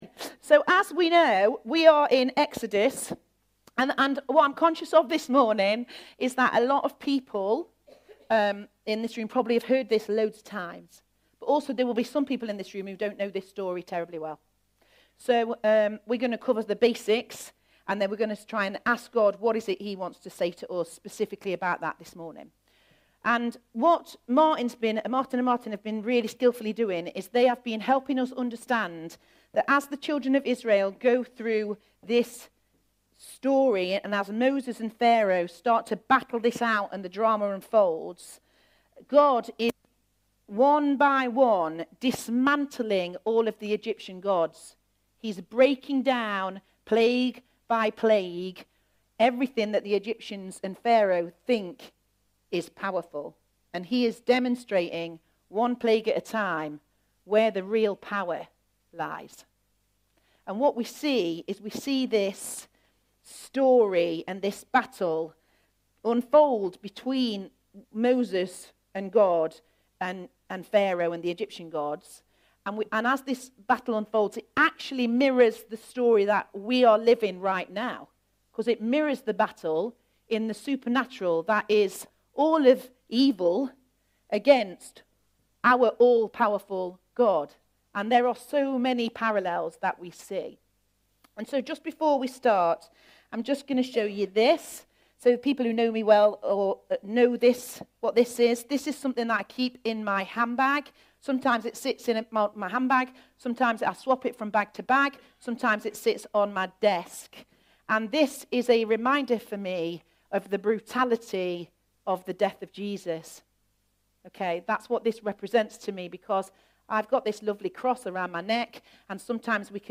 A message from the series "Sunday Morning." Despite the hard reality of judgement and death, the Passover is an event to celebrate because it is a lasting demonstration of Gods promise of salvation for all who will trust in His provision; the lamb, Jesus Christ, who takes away the sins of the world.